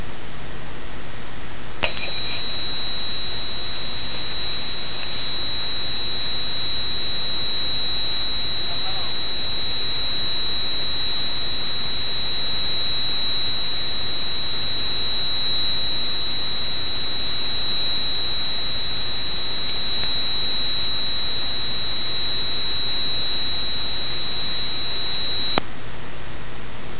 Â You can hear it modulate not unlike this recording below of a tone being modulated by speech.
Hello with tonal echo
subliminals-through-tonal-echos.mp3